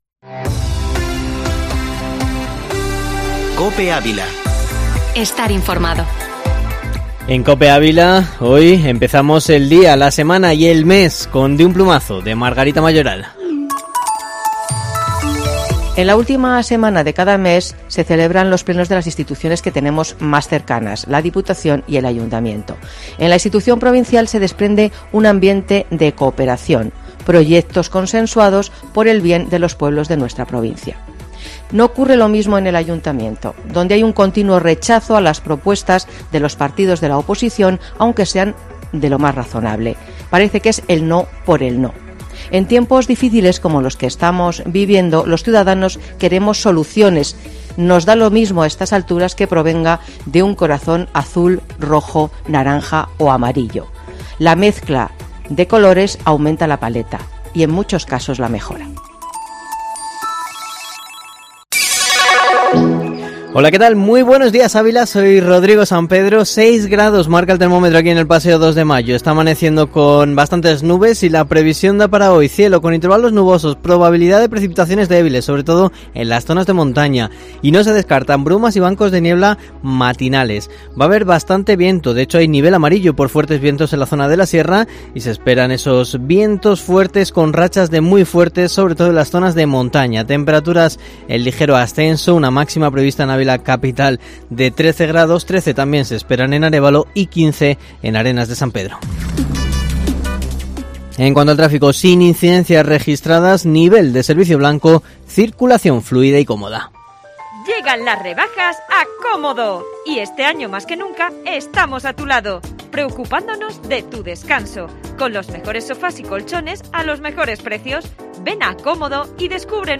Informativo matinal Herrera en COPE Ávila 01/02/2021